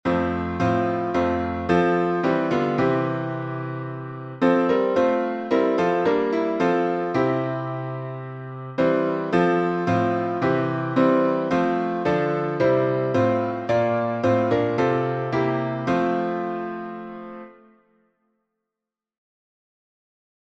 I Love Thy Kingdom, Lord — F major.